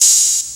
oh_metro_fat.wav